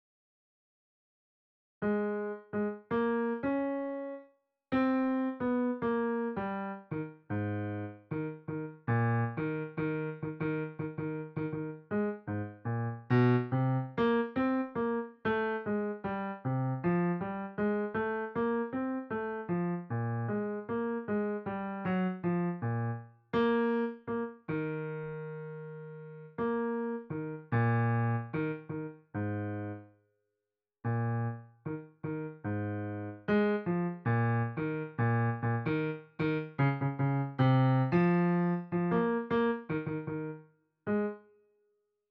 Øvefil for bass fra t. 47 (wav)
I got rhythm ~ bass fra t. 47.WAV